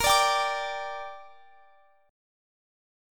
Listen to A#6 strummed